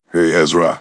synthetic-wakewords
ovos-tts-plugin-deepponies_Kratos_en.wav